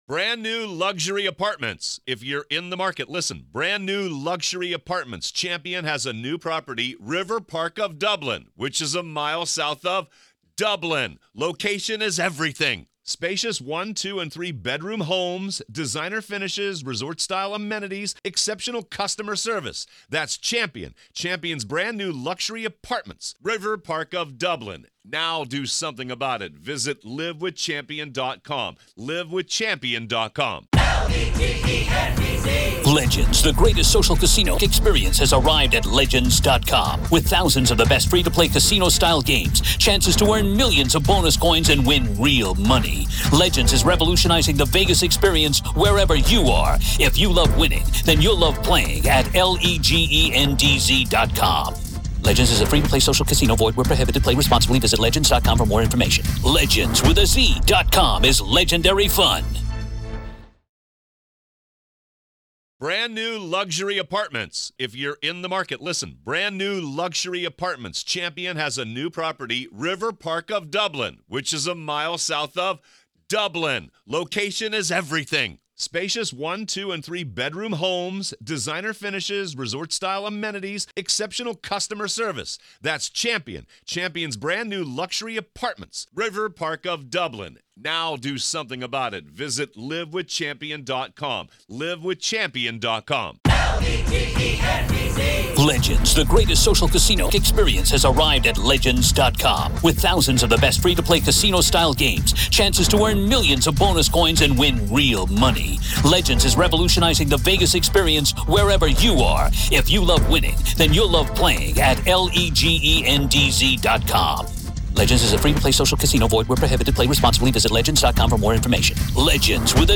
True Crime Today | Daily True Crime News & Interviews / How Many 'Dates' Did Rex Heuermann Go On?